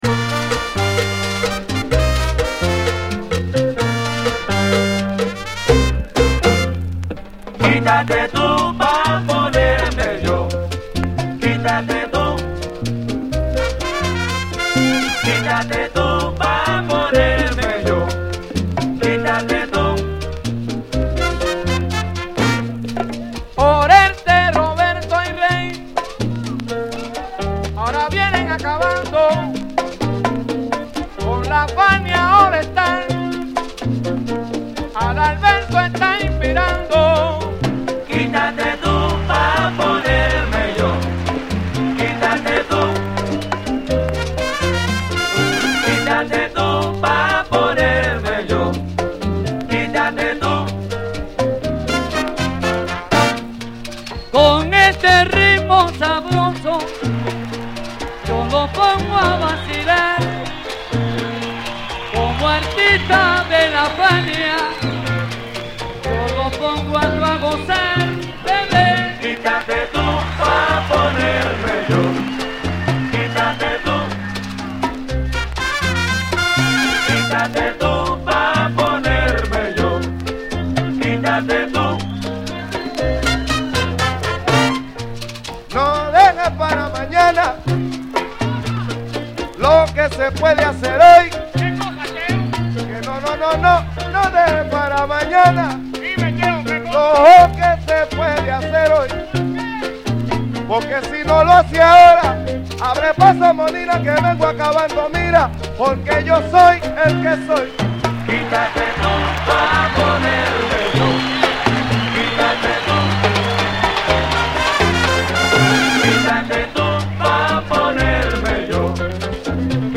キレの良いホーンや華のあるコーラス/ギターなどがホッコリ軽快に絡む5:15のナイス・ヴァージョン！